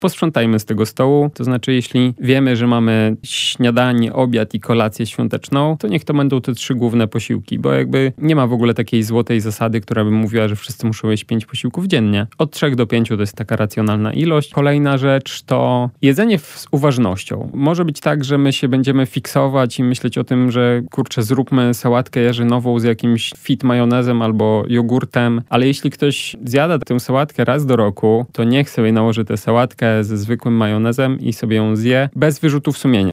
Jak nie przejeść się w święta? Dietetyk radzi